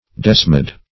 Desmid \Des"mid\, Desmidian \Des*mid"i*an\, n. [Gr. desmo`s